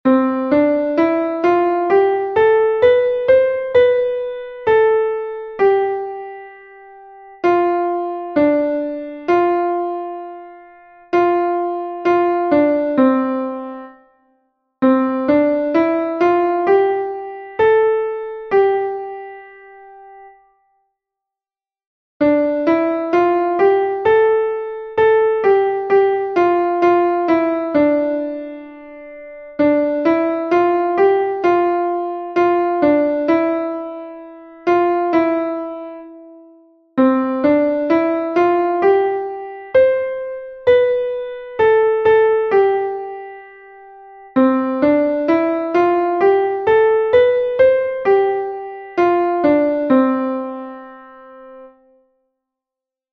g-clef exercise 1